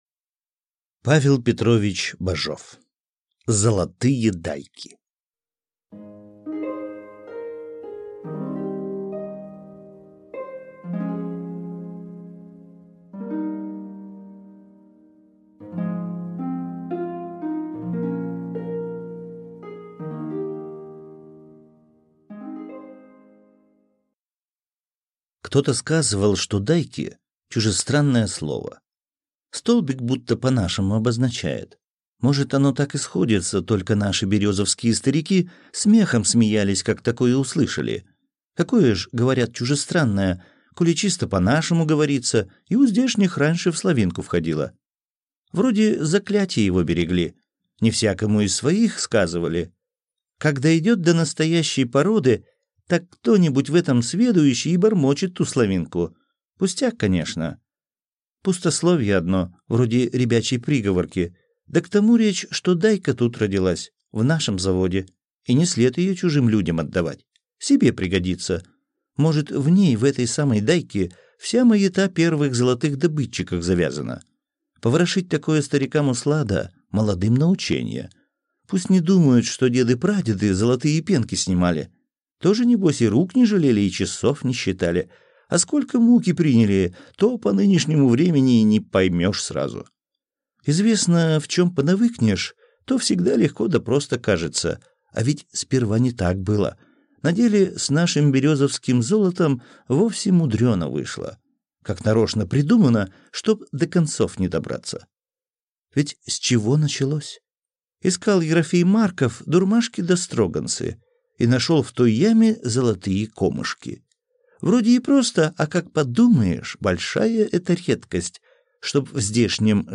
Аудиокнига Золотые дайки | Библиотека аудиокниг